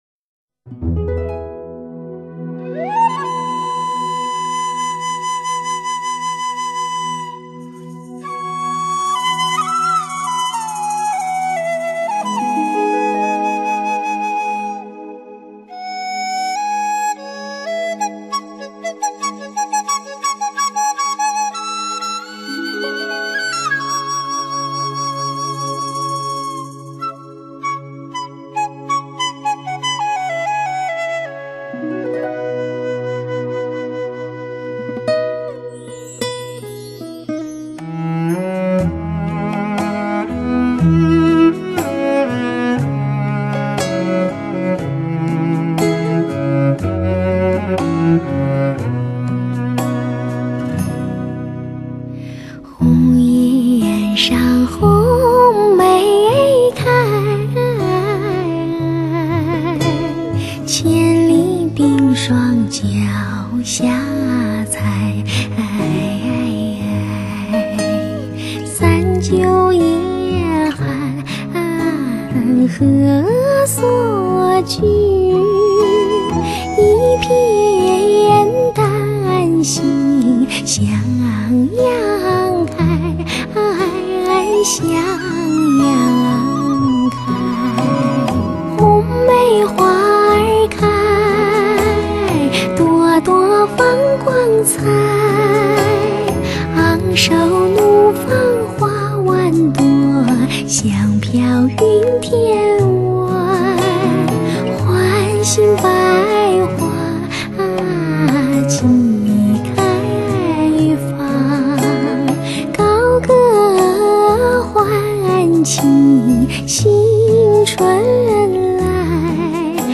清新典雅 飘逸秀美
令人神轻气爽的天使之声
如歌谣般旋律优美、流畅清新的曲子，